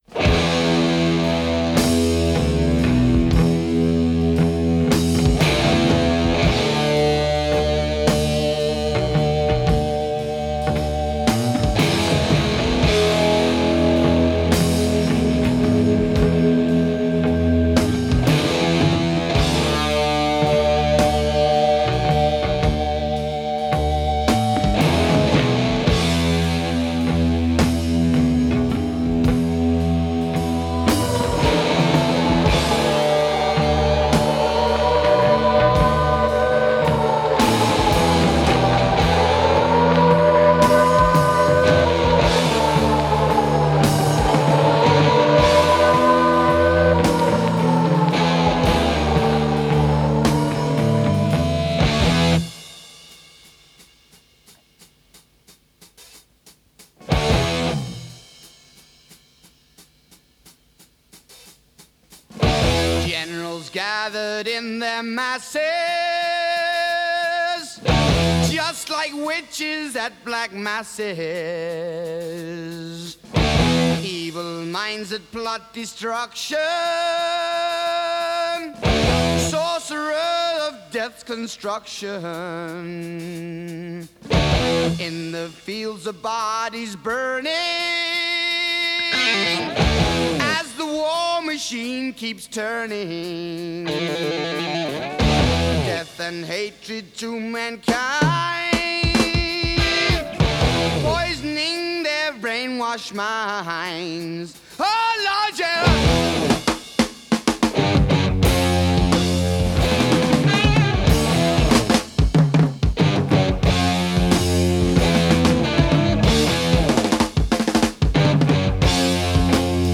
Heavy Metal, Hard Rock